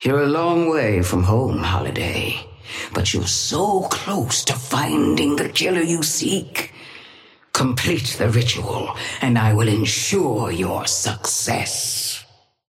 Sapphire Flame voice line - You're a long way from home, Holliday, but you're so close to finding the killer you seek.
Patron_female_ally_astro_start_05.mp3